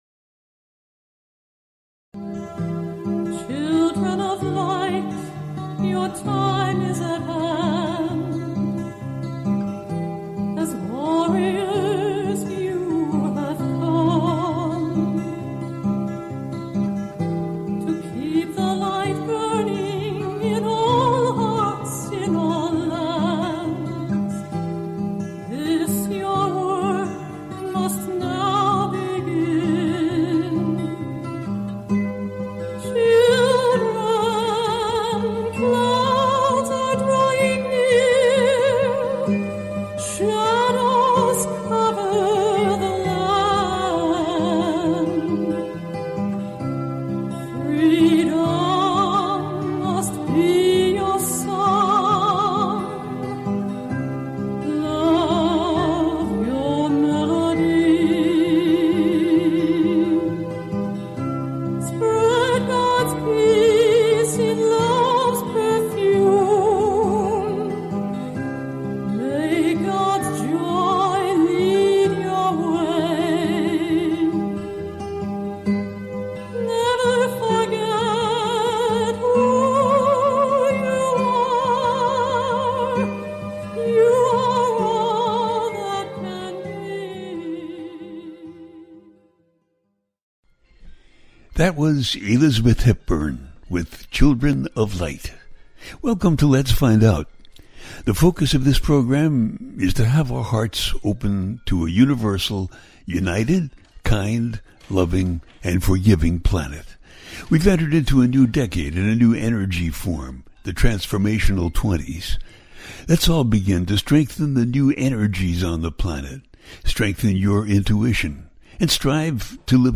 Talk Show Episode
The listener can call in to ask a question on the air.
Each show ends with a guided meditation.